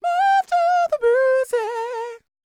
DD FALSET019.wav